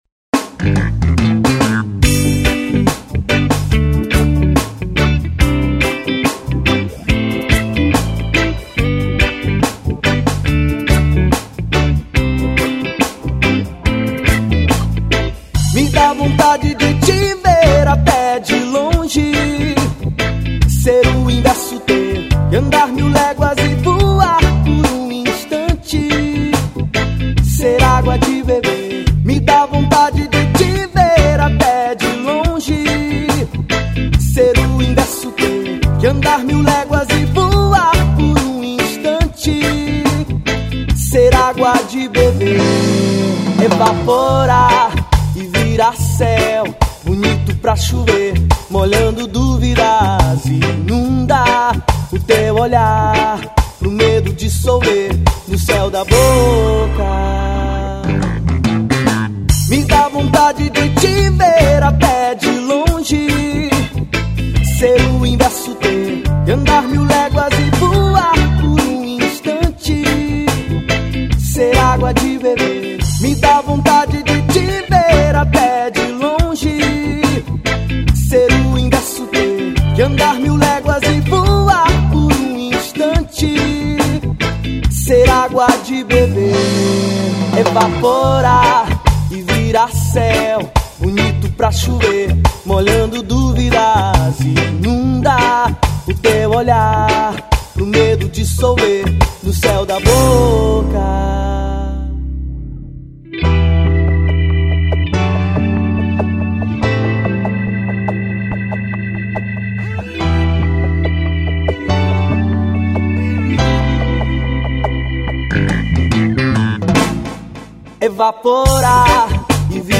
1993   03:11:00   Faixa:     Reggae